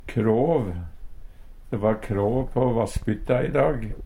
DIALEKTORD PÅ NORMERT NORSK kråv tunt islag Eintal ubunde Eintal bunde Fleirtal ubunde Fleirtal bunde Eksempel på bruk De va kråv på vassbytta i dag, Hør på dette ordet Ordklasse: Substantiv hokjønn Attende til søk